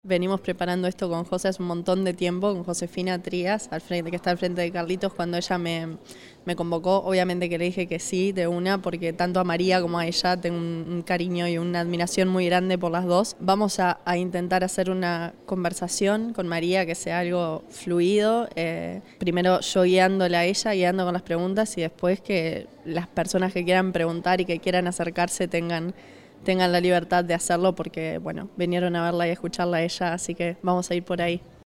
Cierre del ciclo Juntas en Marzo, en el Centro Cultural Carlitos del Municipio de Las Piedras